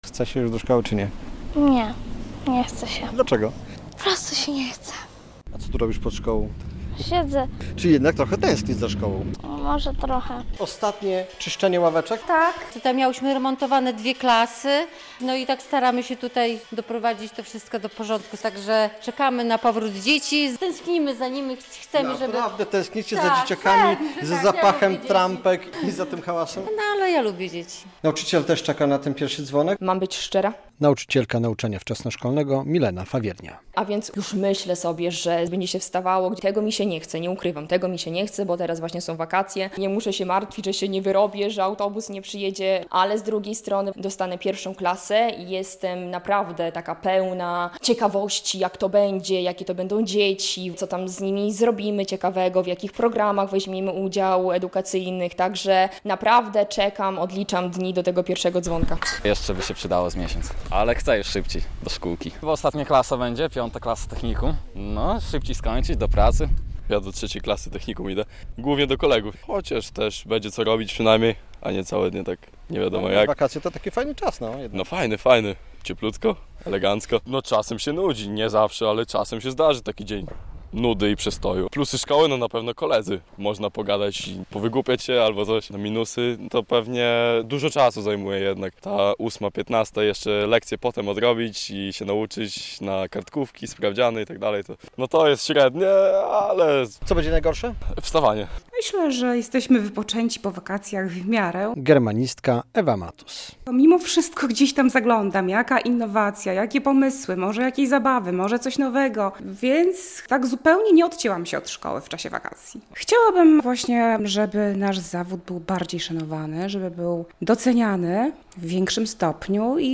Uczniowie i nauczyciele czekają na pierwszy dzwonek - relacja